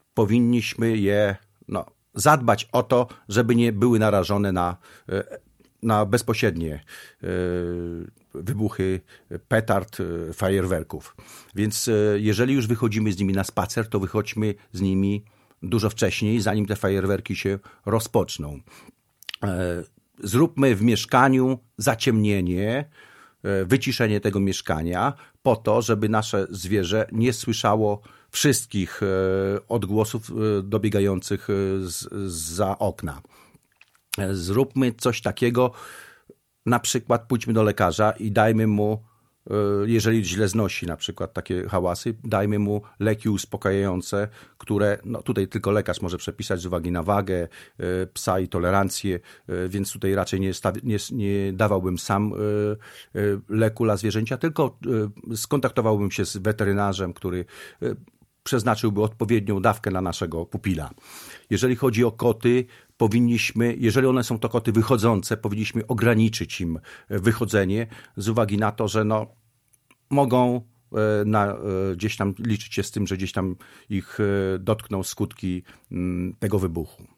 gość Radia 5